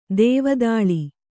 ♪ dēva dāḷi